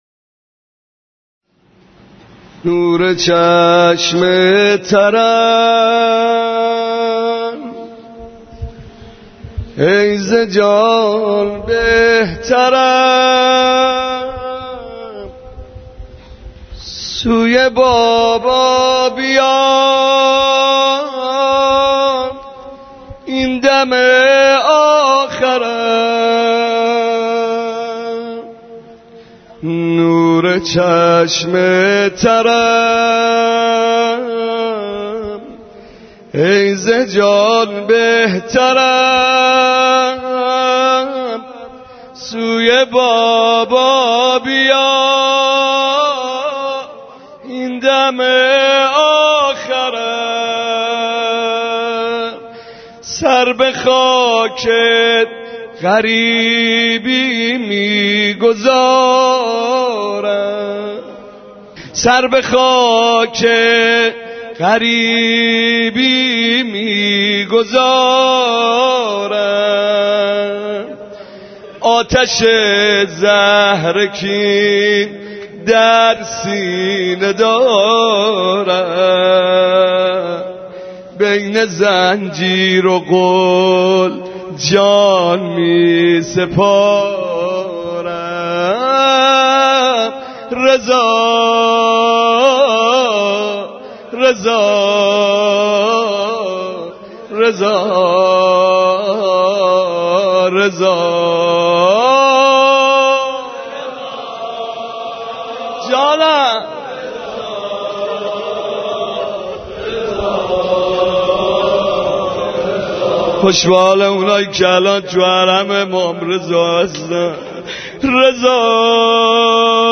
روضه و مرثیه ها